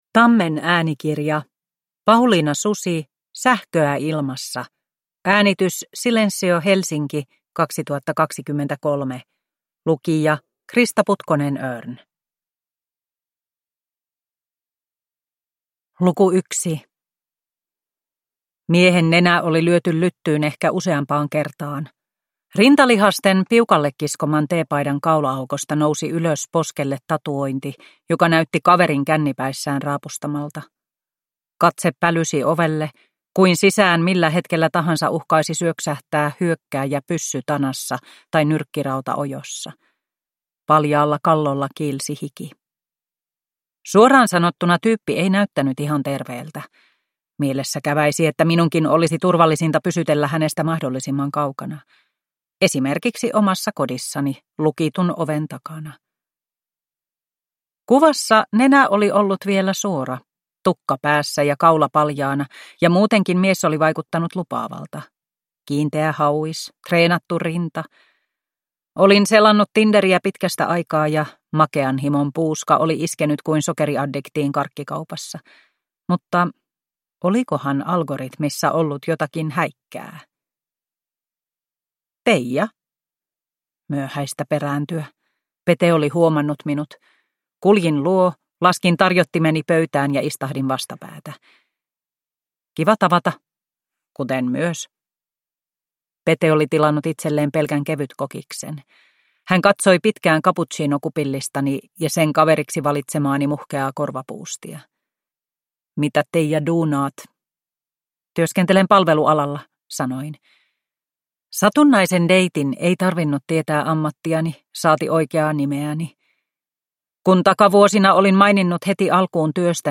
Sähköä ilmassa – Ljudbok – Laddas ner